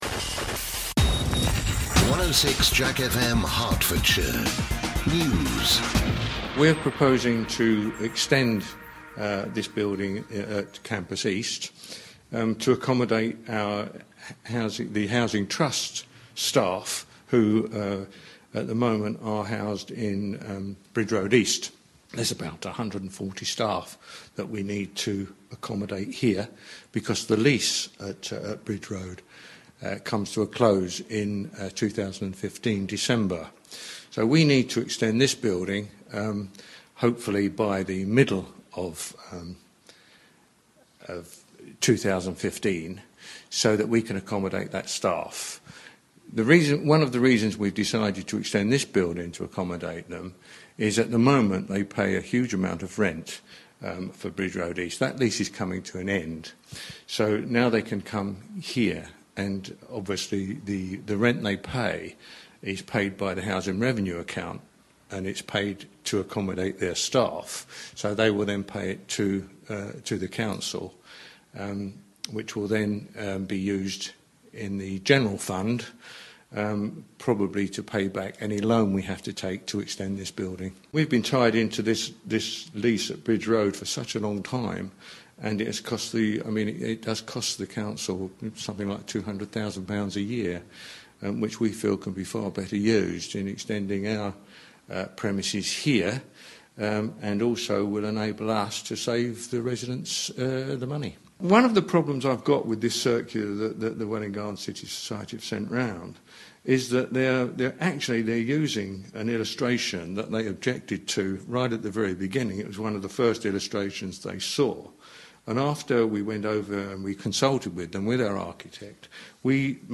Welwyn Hatfield Council Leader John Dean responds to claims the authority hasn't consulted properly on the plans...